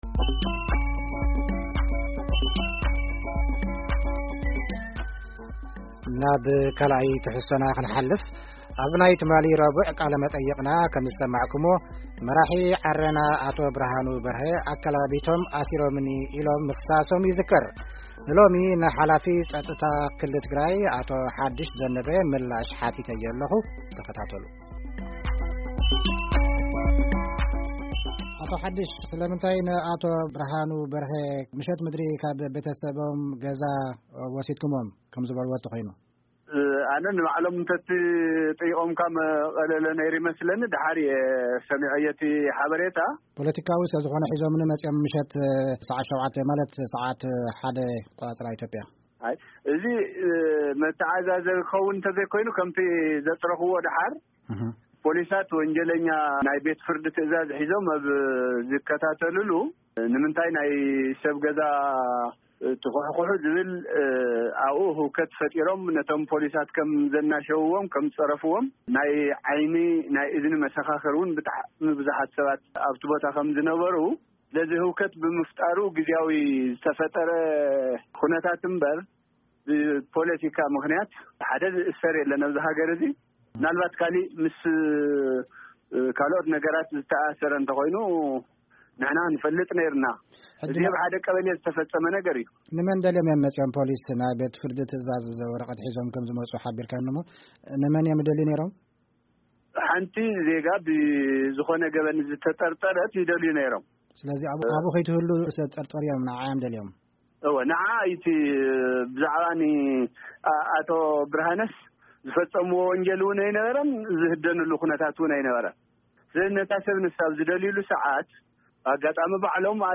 ቃለ-መጠይቕ ምስ ኣቶ ሓዲሽ ዘነበ